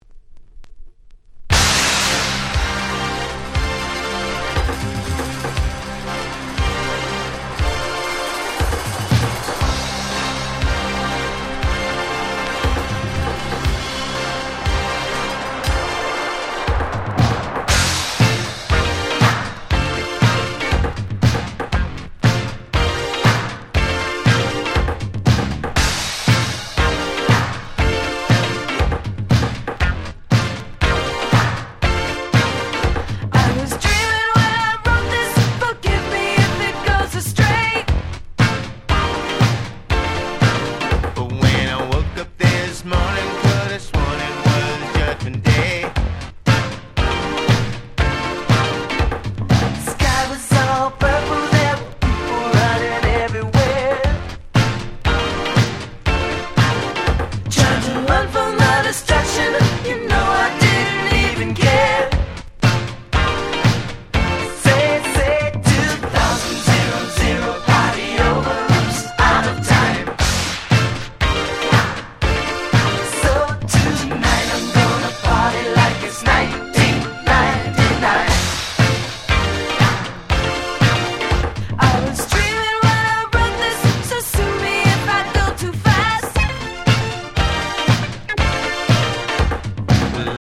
82' Super Hit Pops / R&B / Disco !!
80's Dance Classics